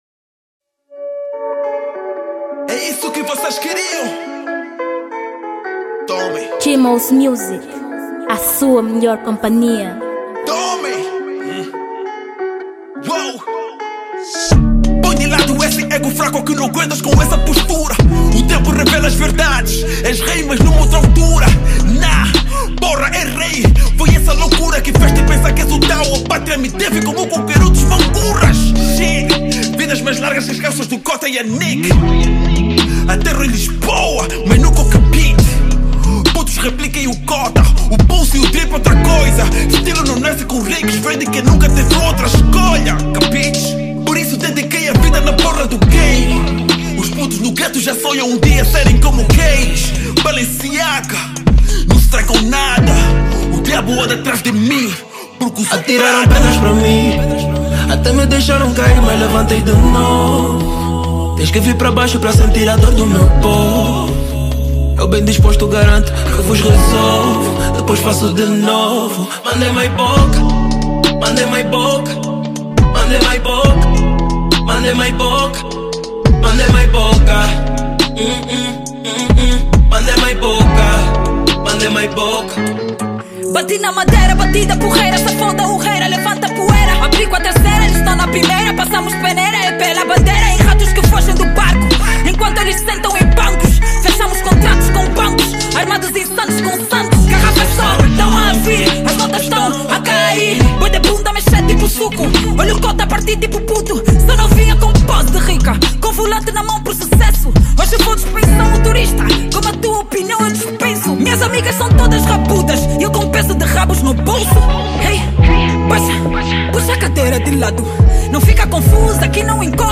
Gênero: Drill